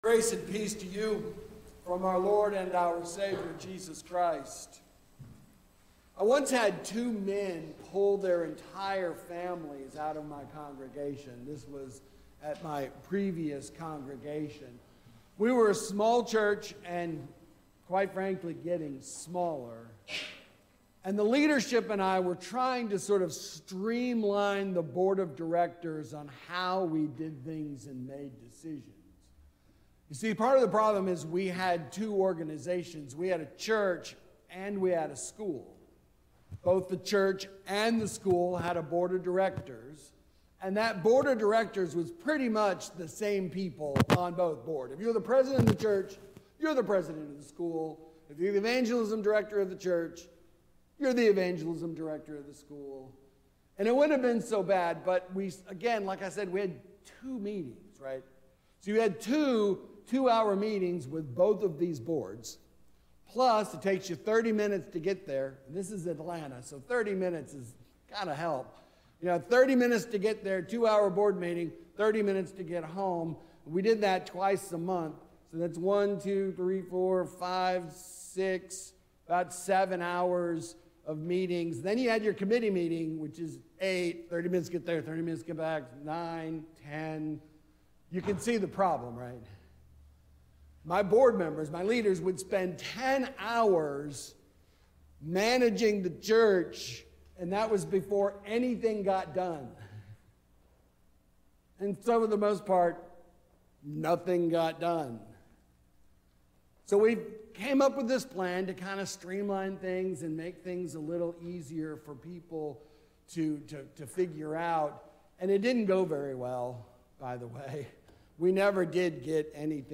Sermon for The Fourth Sunday after The Epiphany